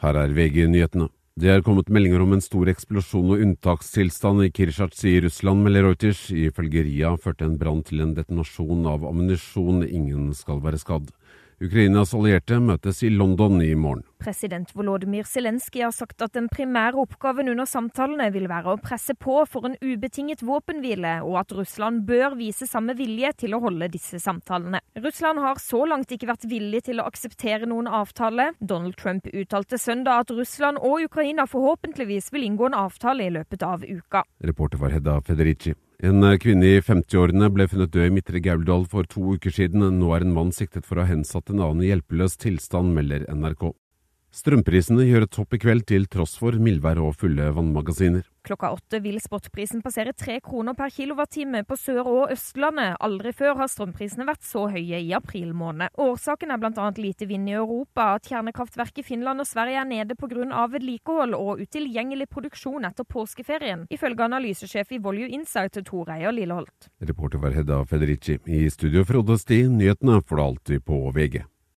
1 Siste nytt fra VG 1:12 Play Pause 5h ago 1:12 Play Pause Redă mai târziu Redă mai târziu Liste Like Plăcut 1:12 Hold deg oppdatert med ferske nyhetsoppdateringer på lyd fra VG. Nyhetene leveres av Bauer Media/Radio Norge for VG.